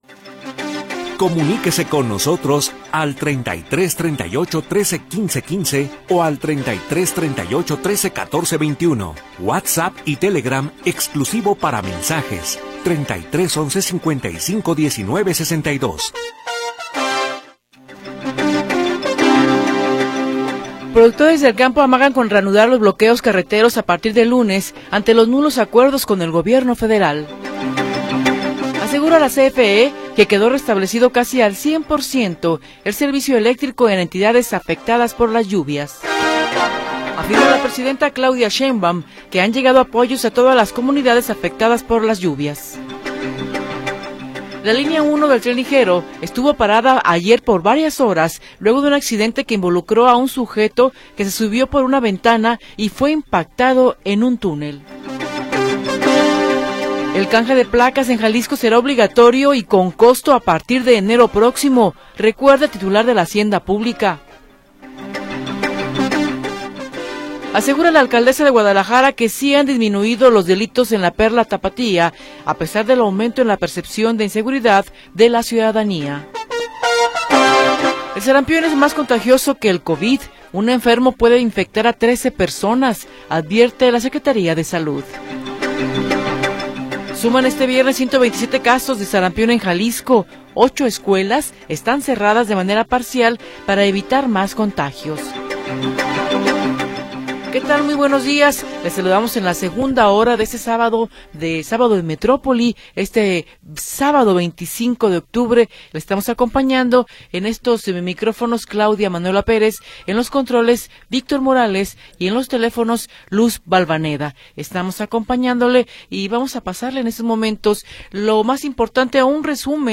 Noticias y entrevistas sobre sucesos del momento
Segunda hora del programa transmitido el 25 de Octubre de 2025.